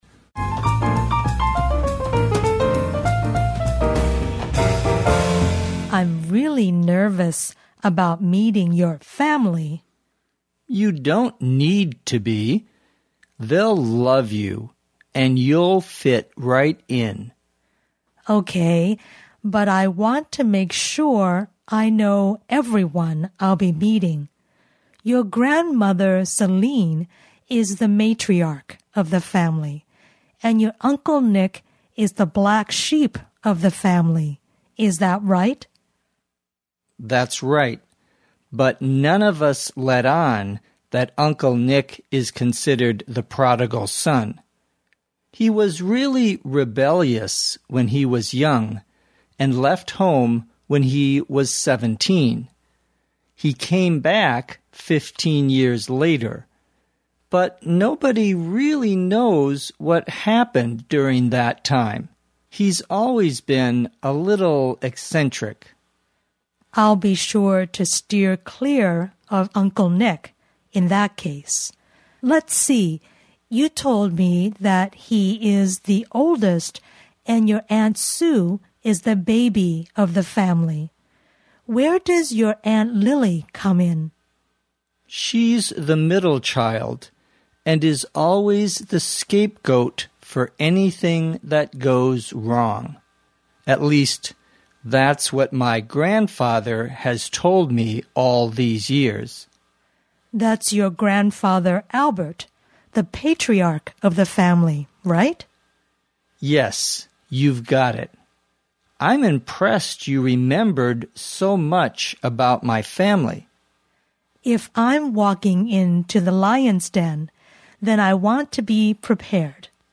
地道美语听力练习:家庭成员